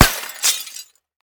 glass02hl.ogg